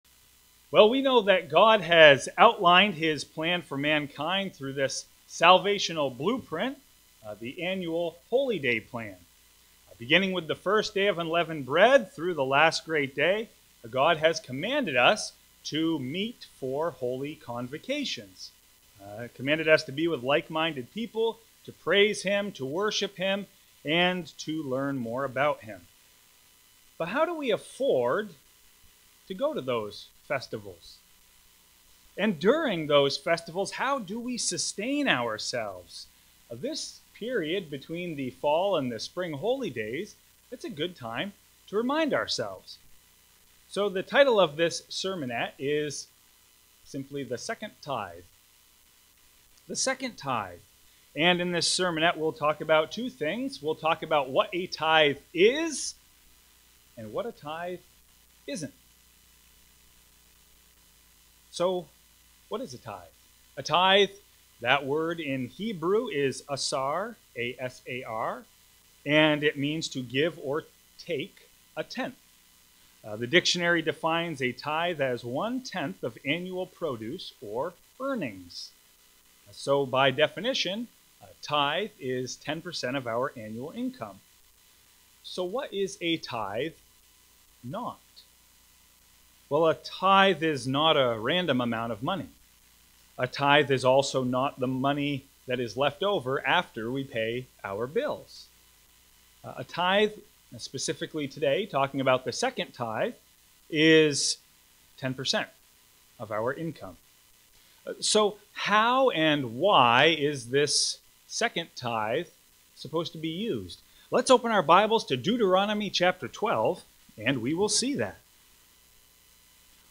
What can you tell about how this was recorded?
Given in Fargo, ND